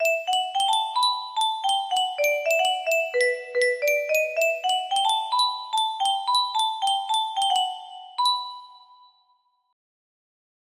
first melody made!1!1 music box melody